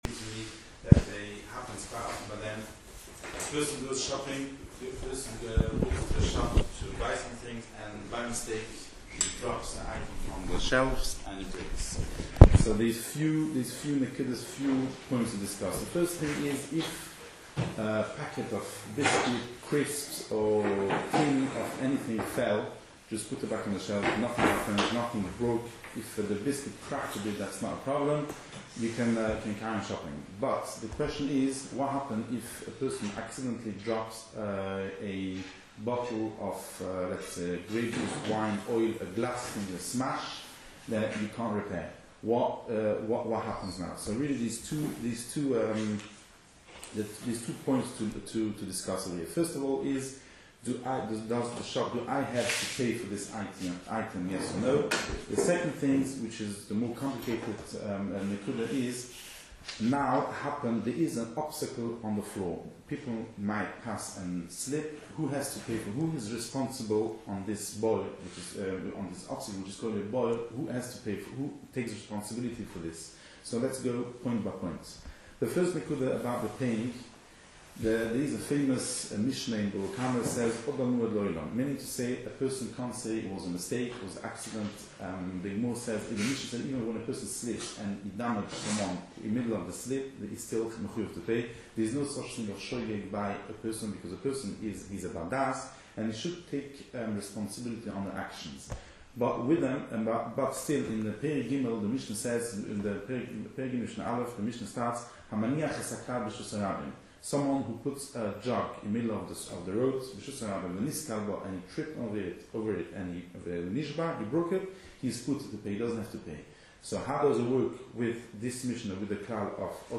Start Your Day The TorahWay Manchester provides daily shiurim on a wide range of topics.